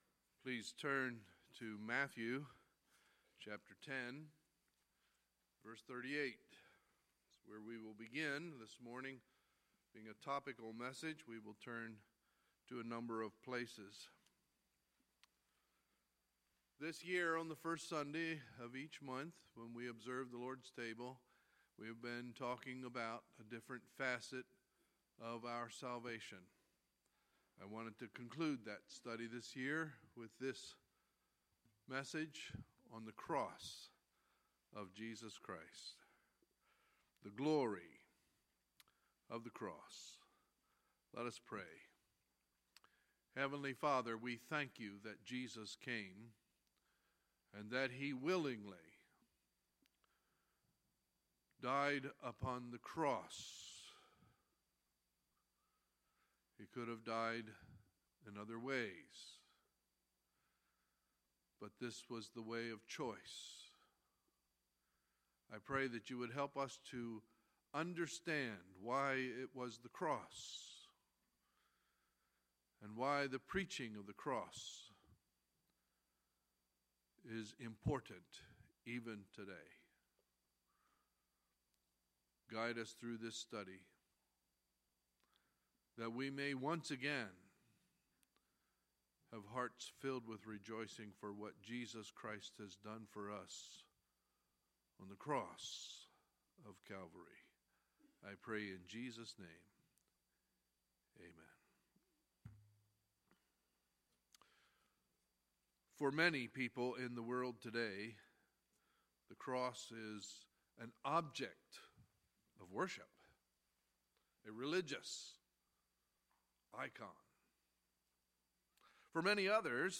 Sunday, December 4, 2016 – Sunday Morning Service